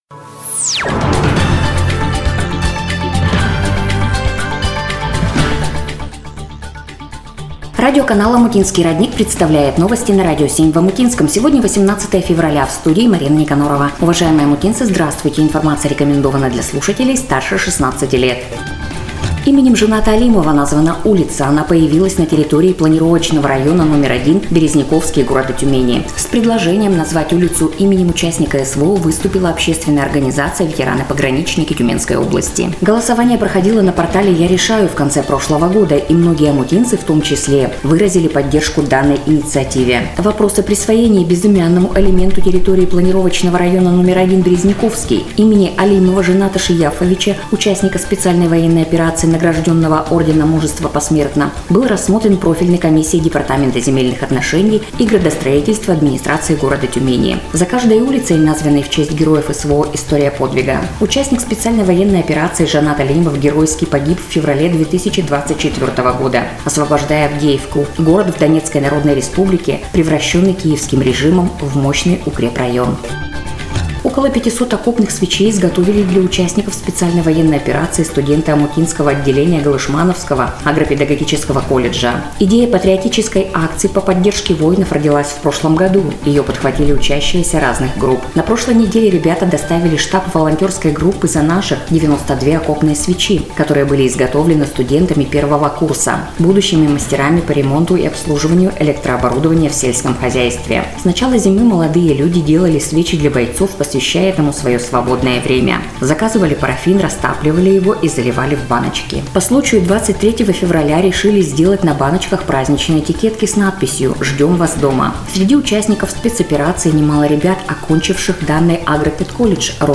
Эфир радиоканала "Омутинский родник" от 18 Февраля 2026 года
NOVOSTI-18.02.26.mp3